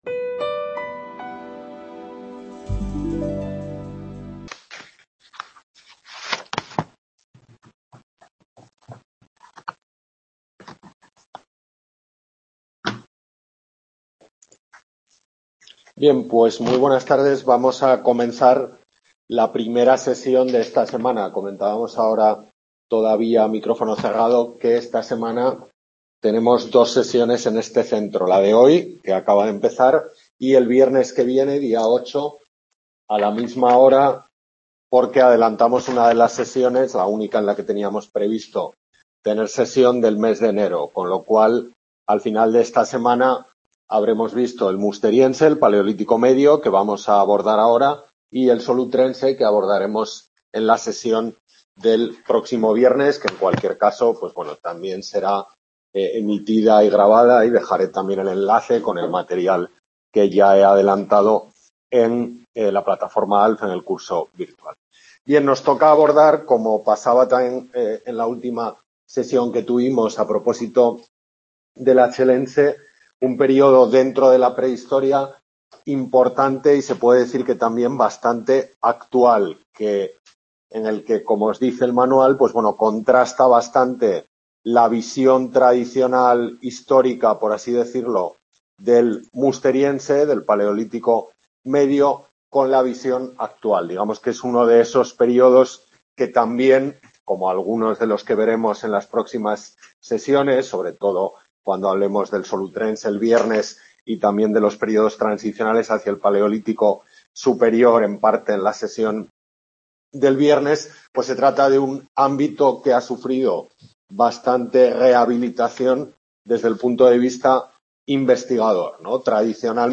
Tutoría de Prehistoria Antigua de la Península Ibérica en el CA de Pamplona